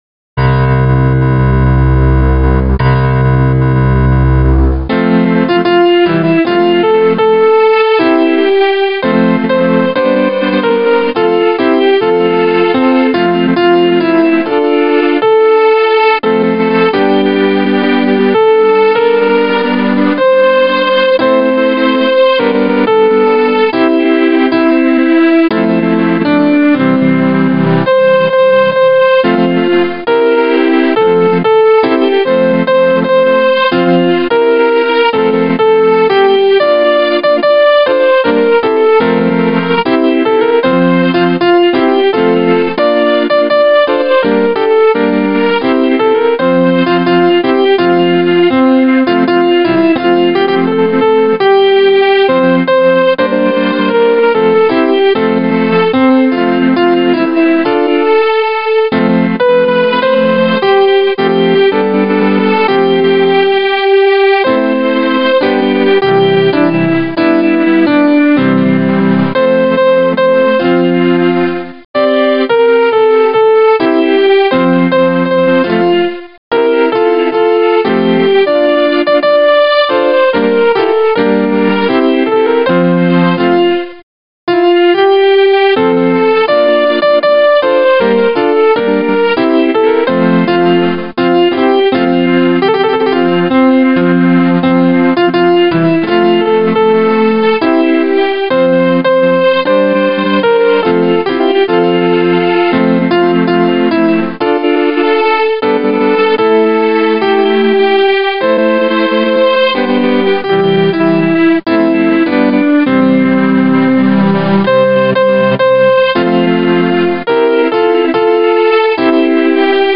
keyboard
Voice used: Grand piano